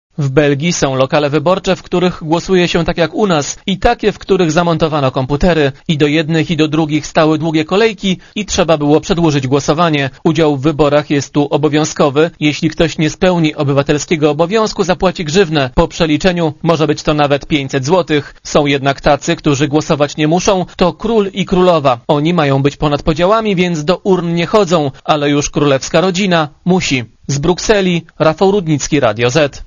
Korespondencja z Brukseli (120Kb)